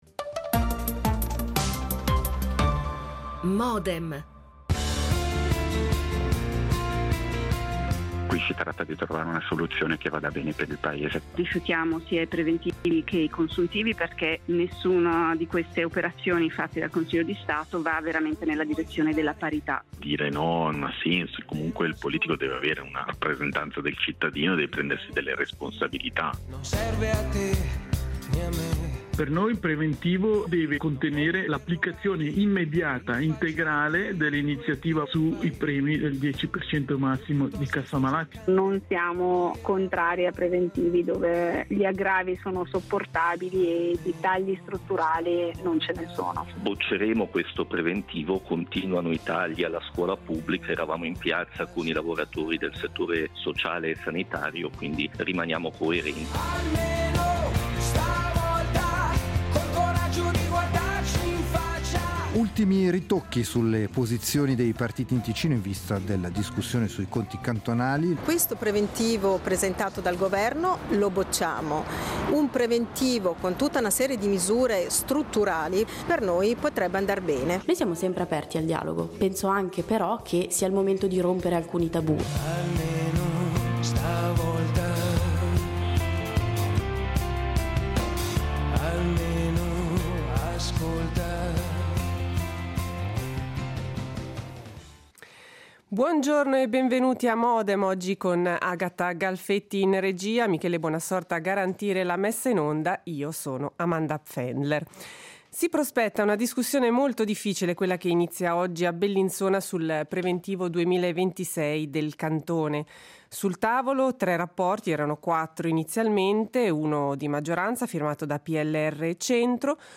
L'attualità approfondita, in diretta, tutte le mattine, da lunedì a venerdì